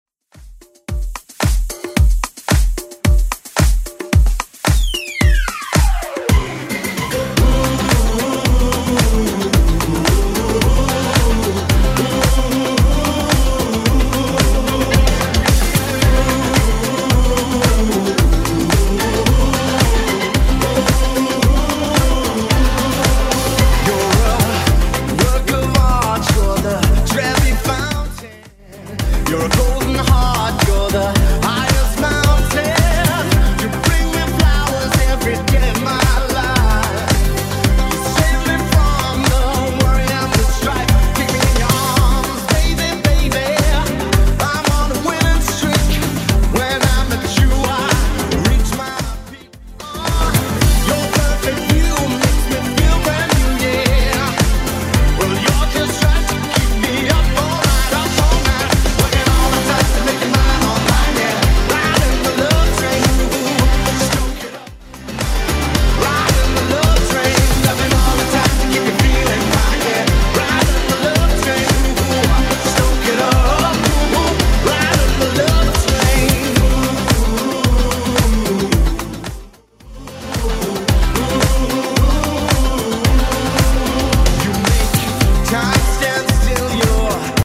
BPM: 111 Time